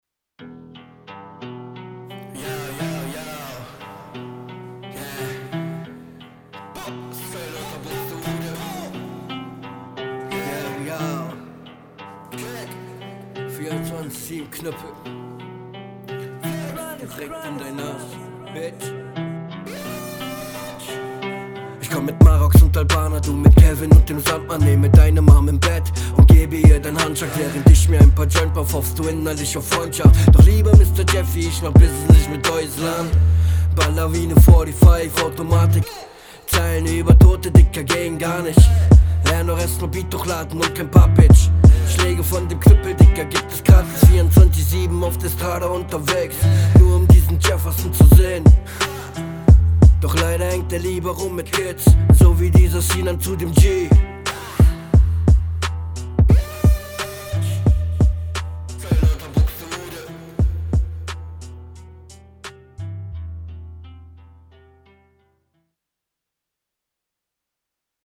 kein gegnerbezug keine coolen lines ganz ok gerappt mehr nicht